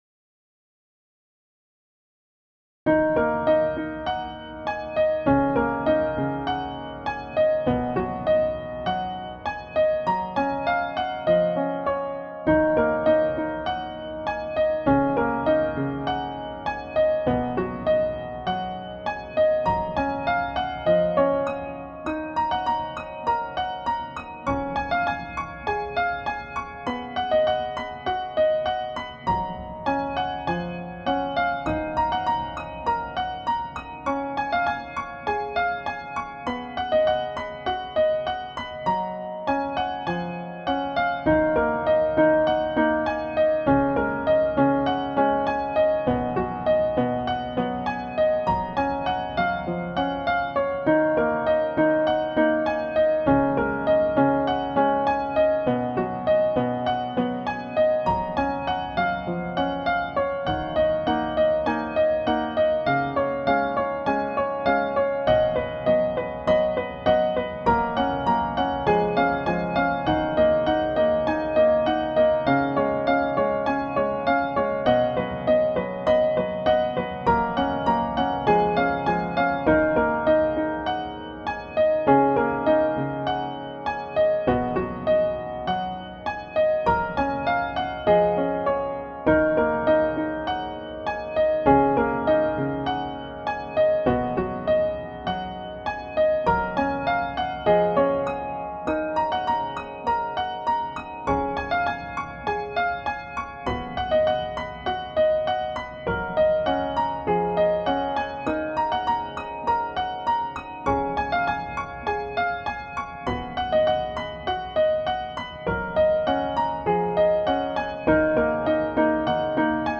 گام: E Minor
تمپو: 100 bpm
ریتم: 4/4